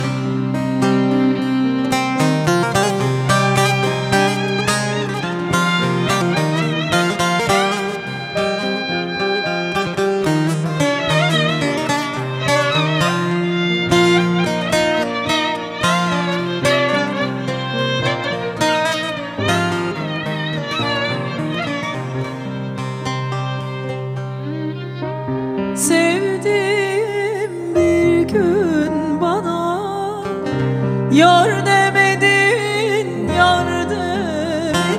Folk-Rock
Жанр: Рок / Фолк